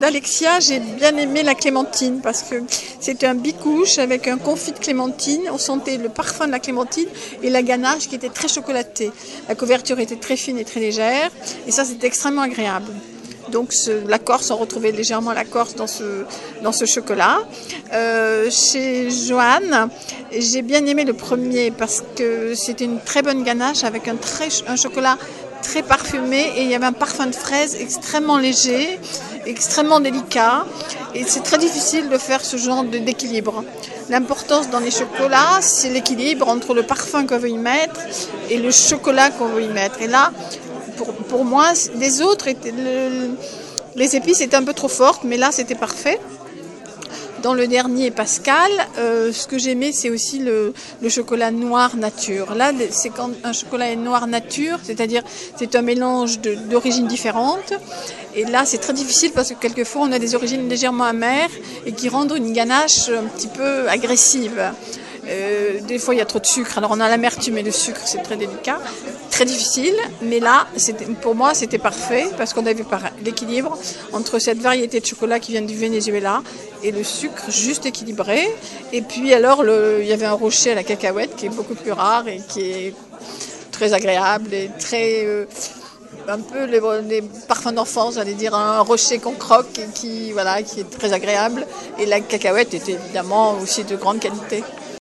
membre du Panel du Club des Croqueurs de Chocolat, au micro